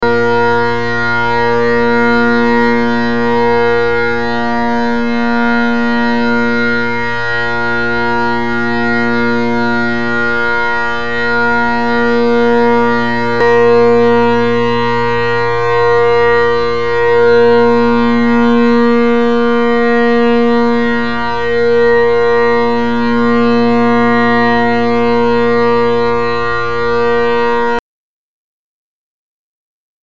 Kron Heritage with Carbon Rocket tenors and Kinnaird bass. Recorded with an mp3 player with recording functionality placed on a table in a carpeted lunch/meeting room. Tuned to 477 Hz.